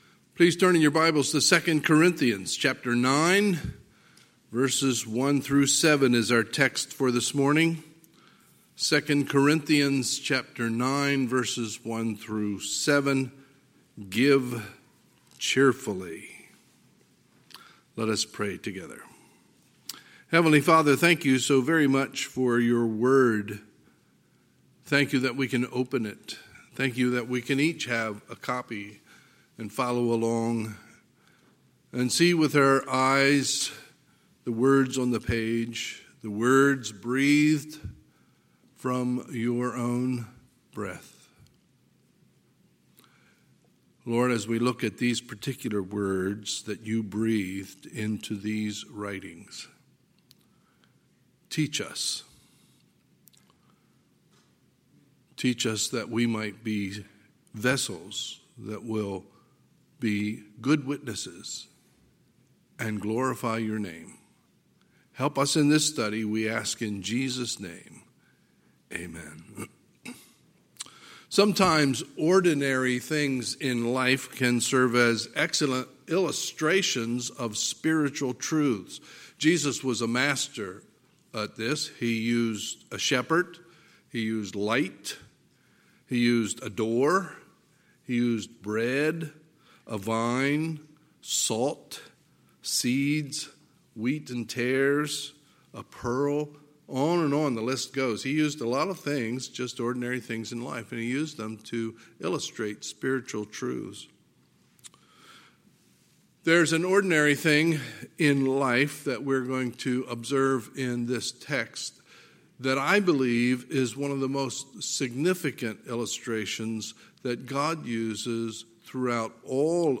Sunday, September 6, 2020 – Sunday Morning Service